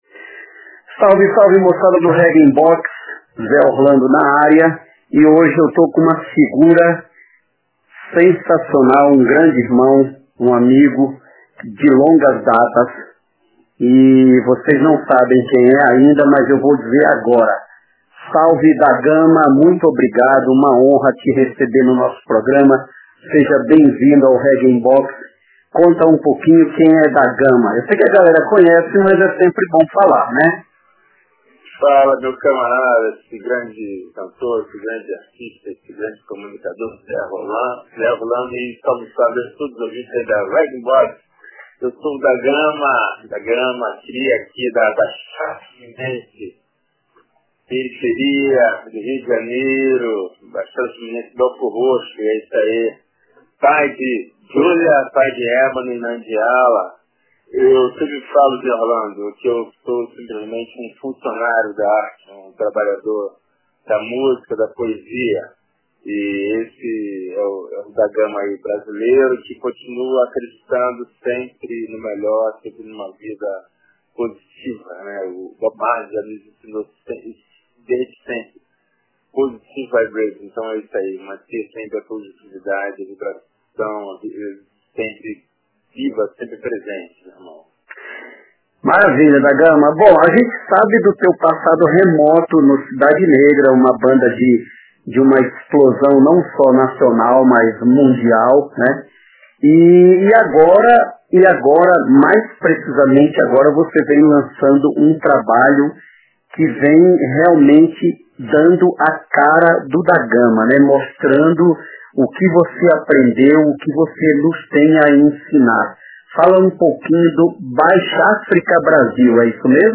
RIB Entrevista